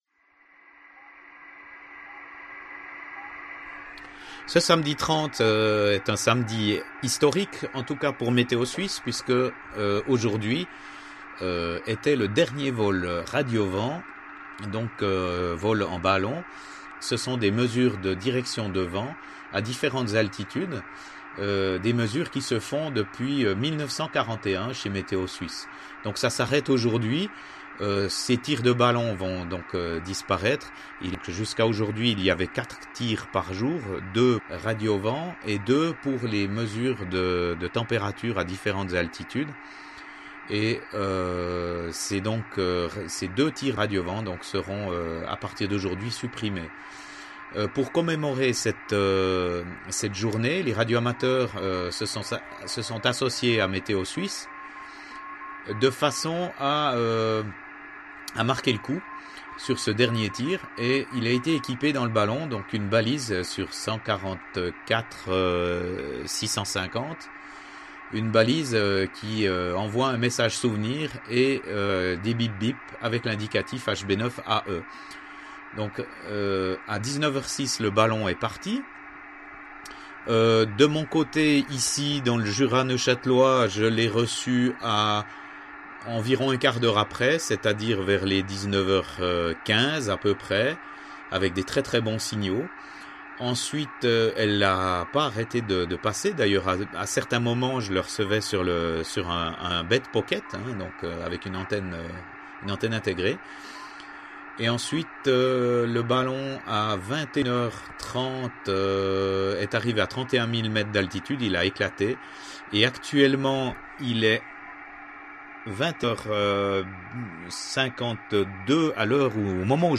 La suite dans ce petit reportage audio: